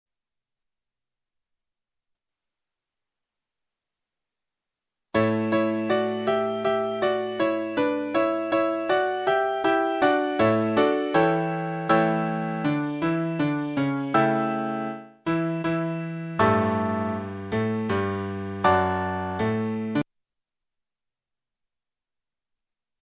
Ex １はテンポの変わりをで演奏した場合を分かりやすいように　で記譜した。
楽譜作成ソフトでできる音で、はなはだ非音楽的だが、テンポは忠実に再現するので聞いてもらいたい。